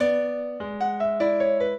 piano
minuet4-6.wav